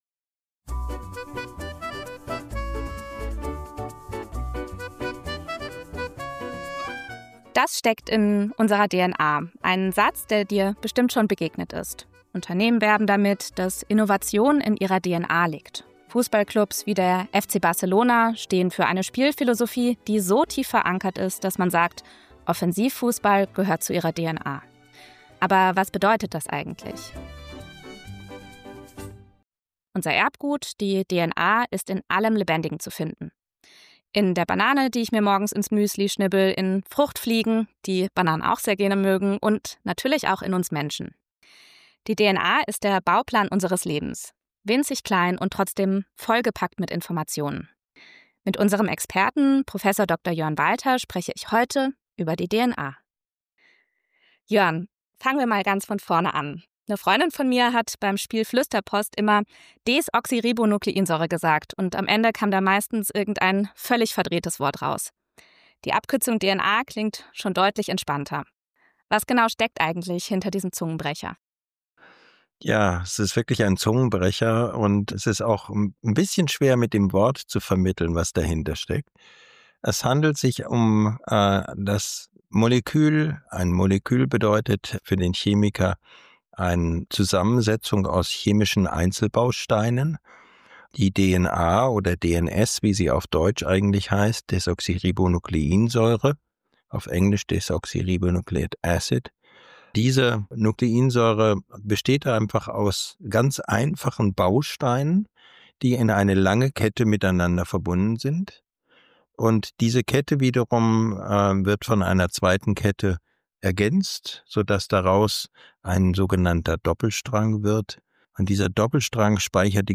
im Gespräch mit dem Genetik-Experten